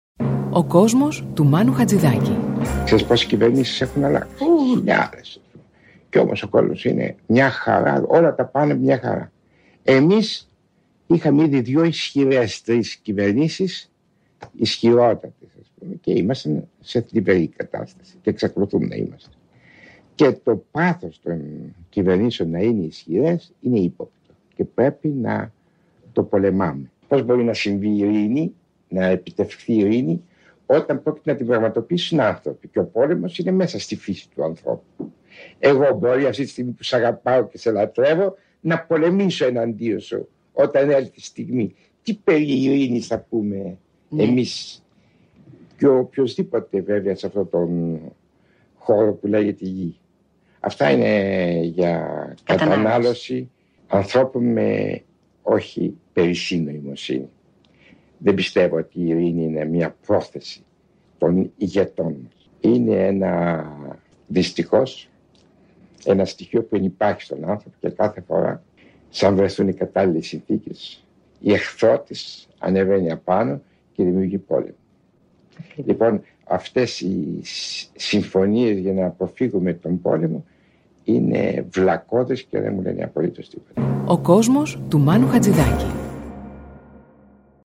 Ακούμε τη φωνή του Μάνου Χατζιδάκι και μπαίνουμε στον κόσμο του.